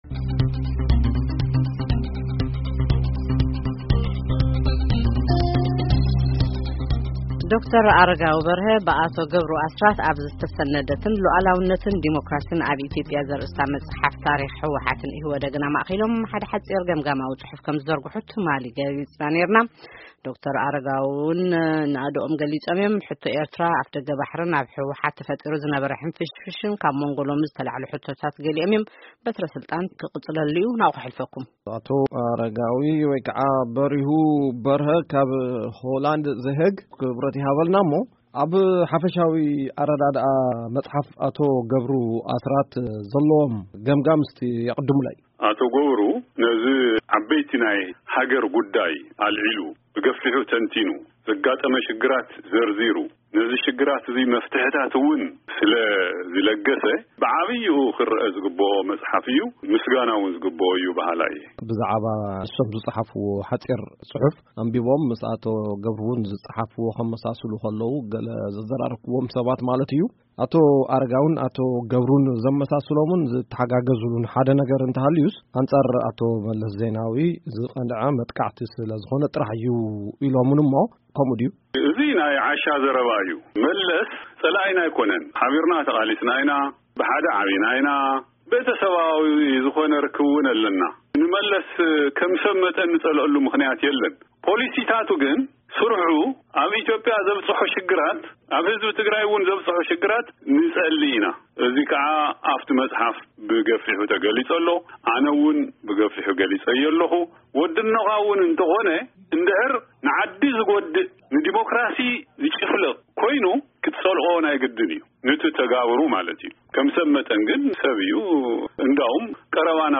1ይን 2ይን ክፋላት ቃለ-መጠይቅ ምስ ዶ/ር ኣረጋዊ በርሀ